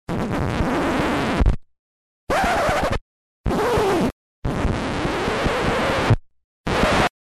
Игла скребет пластинку